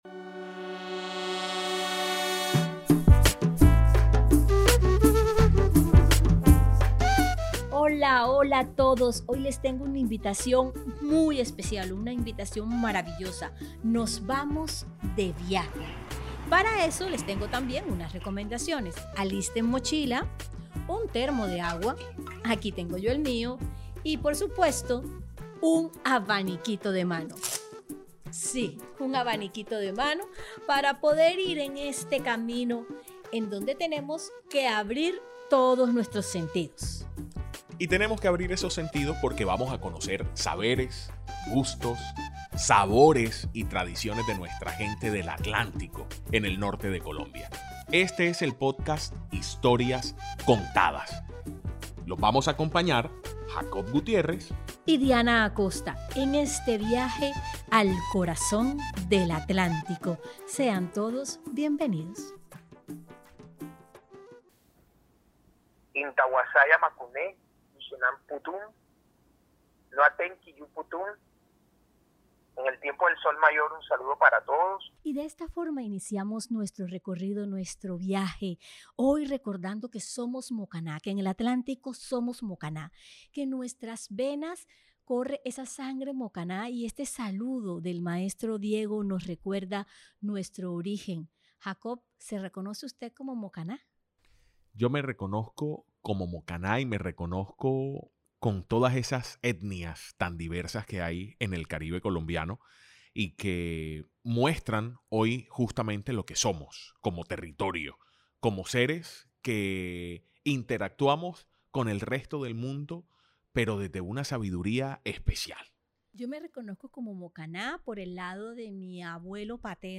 Escucha la gran narración de “Los Mokaná" en Historias contadas por RTVCPlay